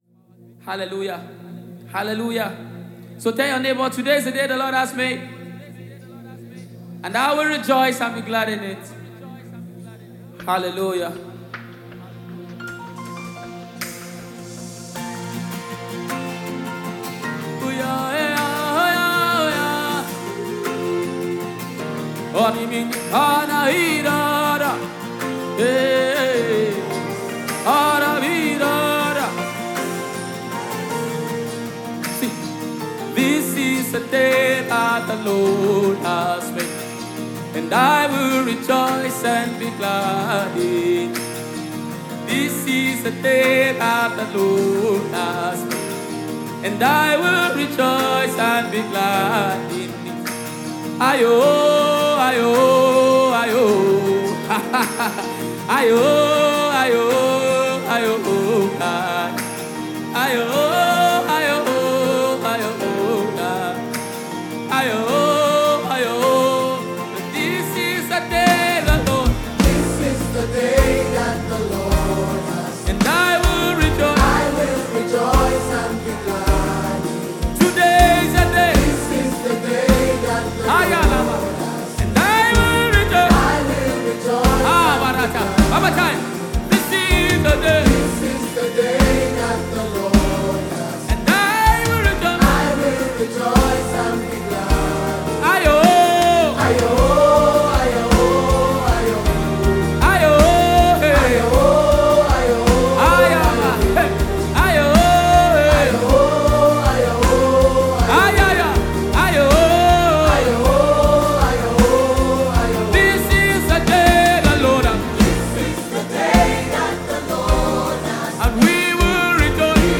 Live recorded.